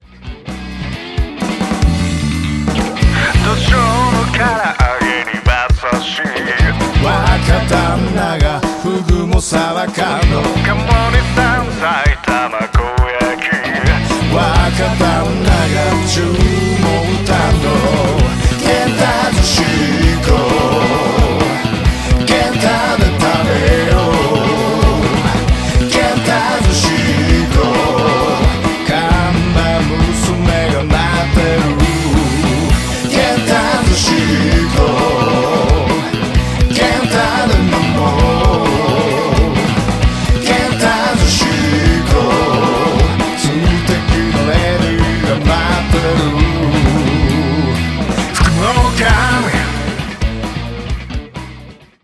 ロックバンド
ちょっぴりいねよな土着ロック 聴いてみませんか？